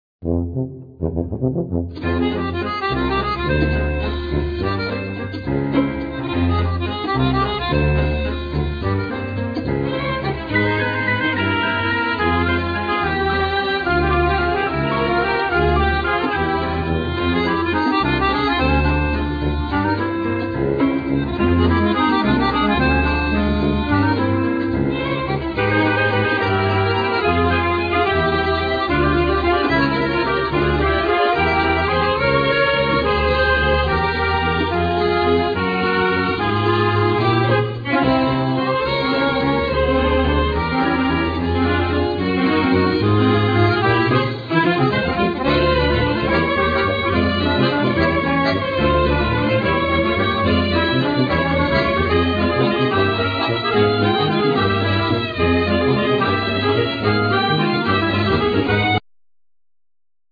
Piano
Accordion
Clarinet
Tuba